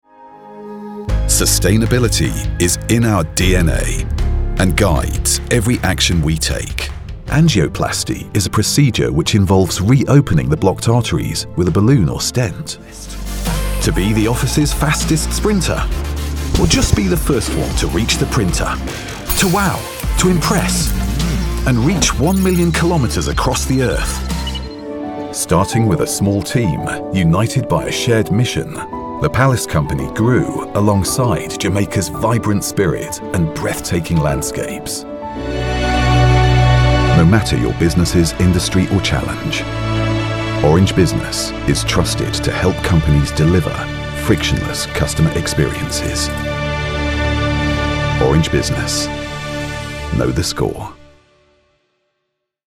Engels (Brits)
Commercieel, Diep, Natuurlijk, Opvallend, Vertrouwd
Corporate